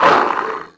c_cerberus_hit3.wav